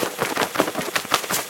assets / minecraft / sounds / mob / bat / loop.ogg